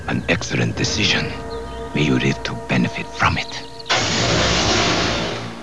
From X-Men: The Animated Series.